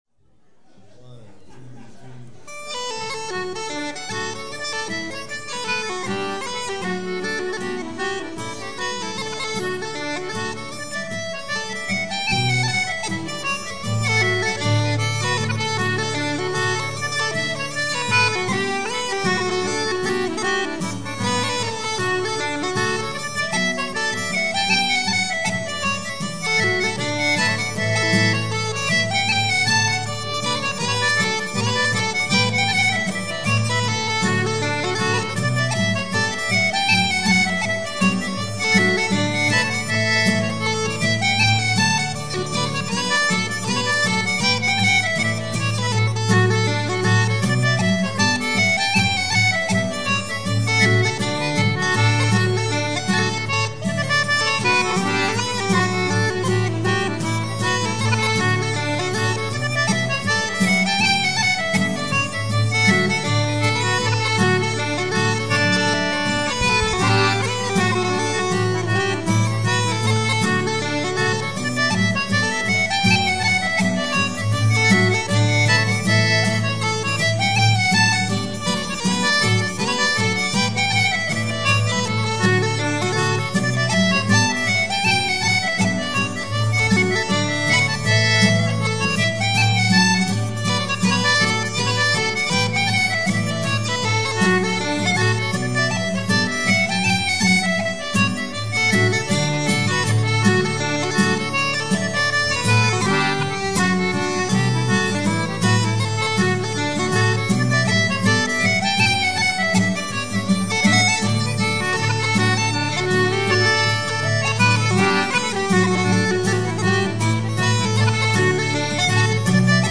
感受下爱尔兰的传统音乐
hornpipes2.mp3